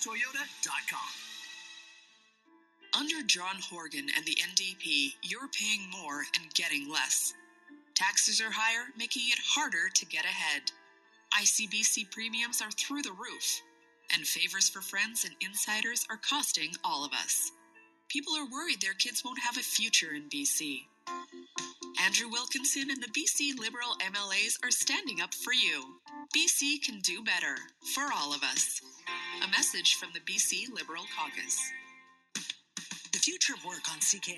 BC Liberal radio ad 0408